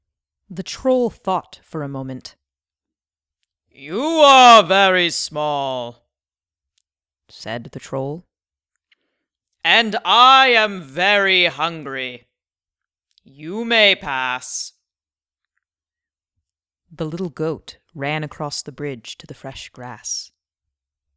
Mezzo-soprano, character actor, audiobooks, games, commercials
Sprechprobe: eLearning (Muttersprache):